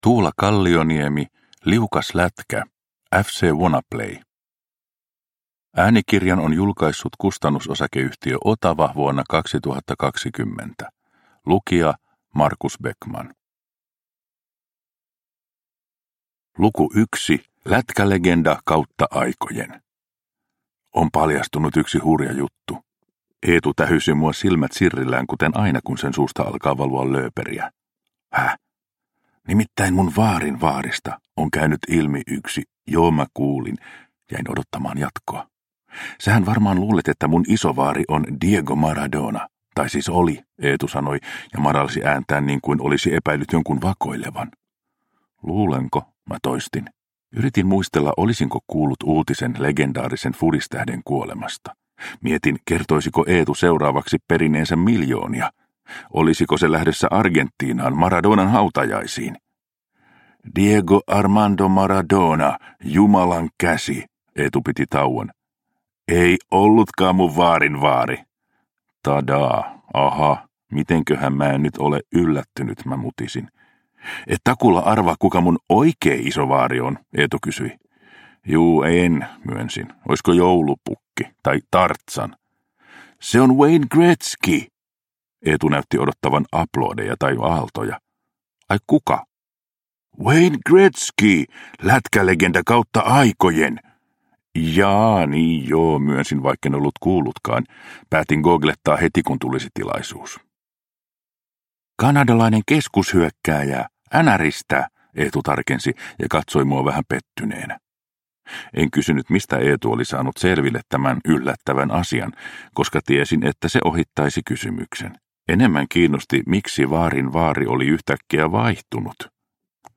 Liukas lätkä – Ljudbok – Laddas ner